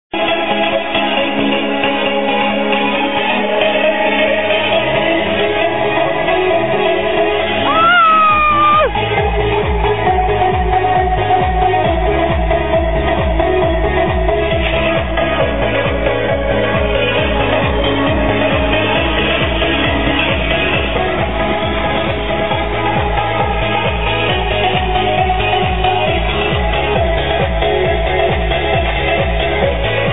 The tune is kinda quiet, its kinda old too apparently...